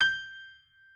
pianoadrib1_63.ogg